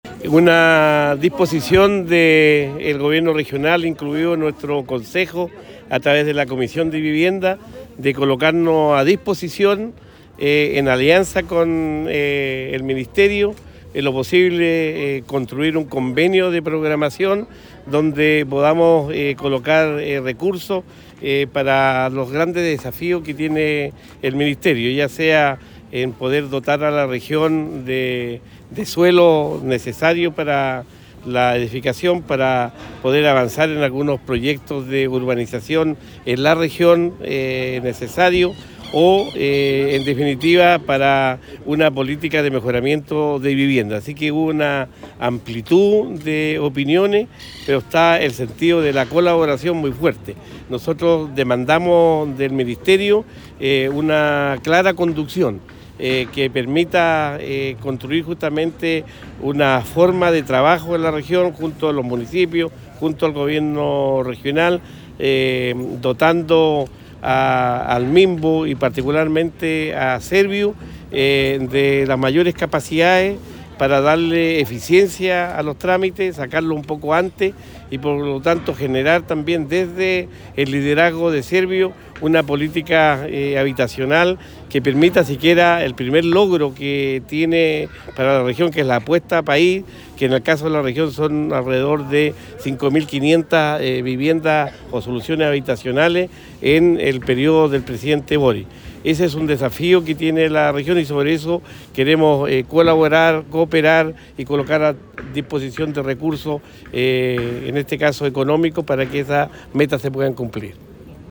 Cuña_Gobernador_visita-Ministro-Vivienda.mp3